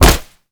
kick_hard_impact_06.wav